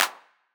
ITA Clap.wav